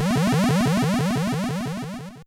Folder: sfx